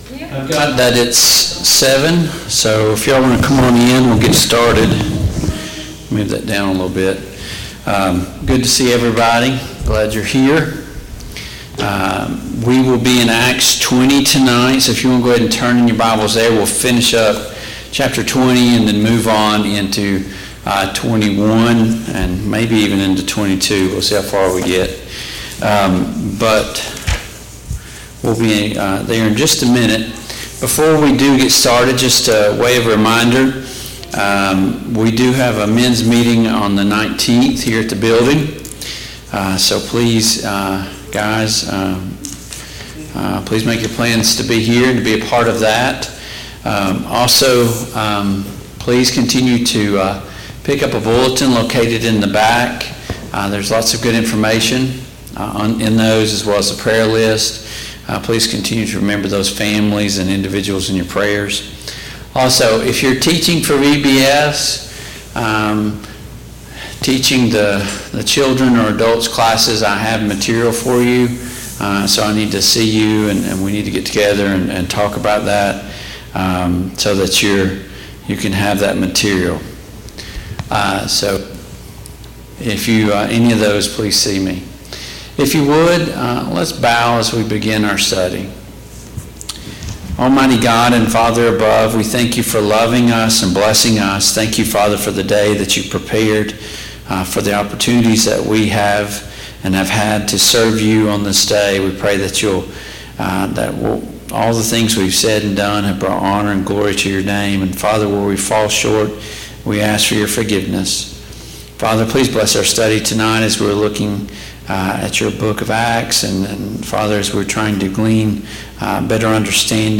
Passage: Acts 20:17-38; Acts 21:1-14 Service Type: Mid-Week Bible Study